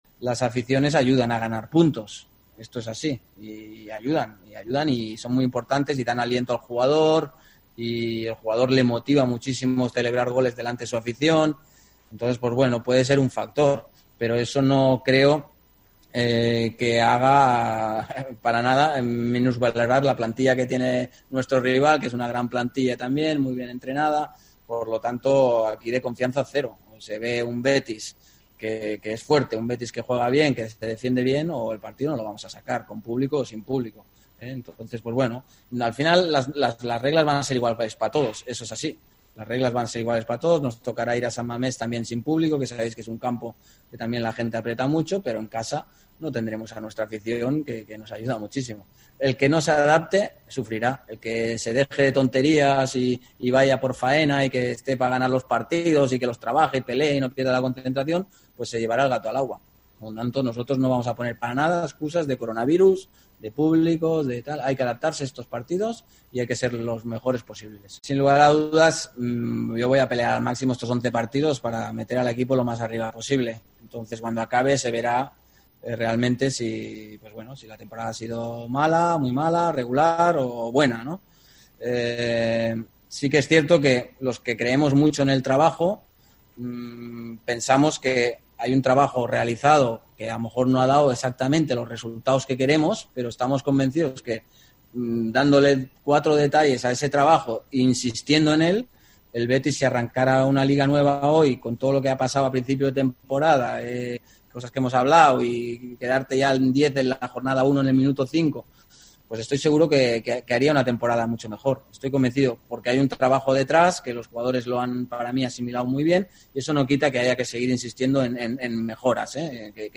RUBI, EN LA PRIMERA RUEDA DE PRENSA TRAS LA VUELTA AL TRABAJO DEL REAL BETIS
Aquí puedes escuchar lo más interesante de las palabras del entrenador verdiblanco.